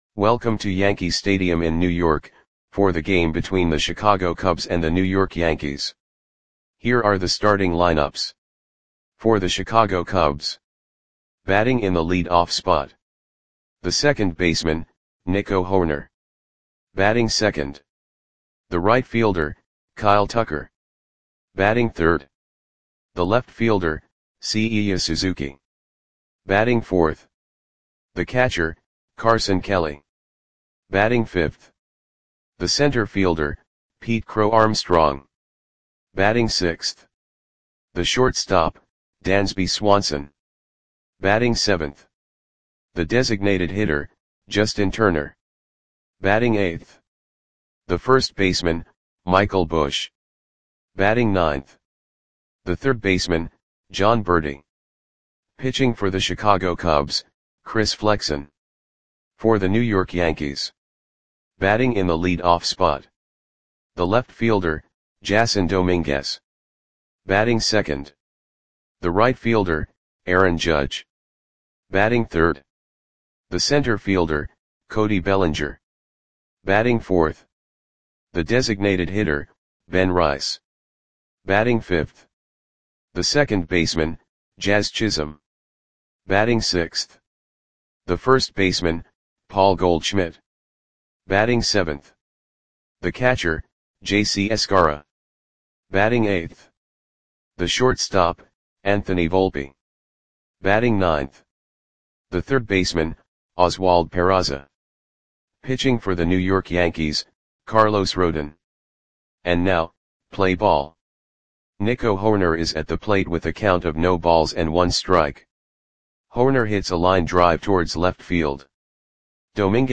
Audio Play-by-Play for New York Yankees on July 11, 2025
Click the button below to listen to the audio play-by-play.